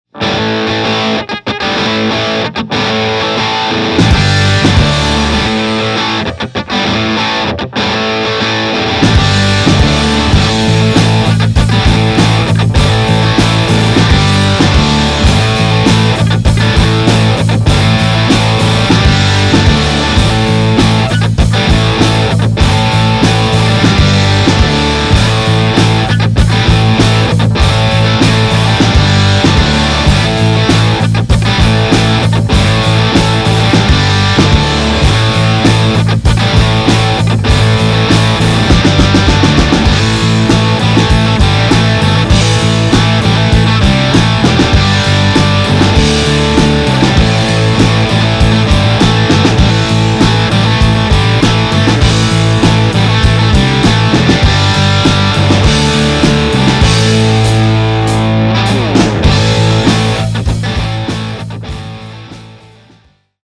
I think you'll be blown away by how big it sounds in the context of a band!!!
The rhythm section was recorded full out balls to the wall!! 2 watts!!
Rain - java boost into TriFly into 212 Jensen blackbirds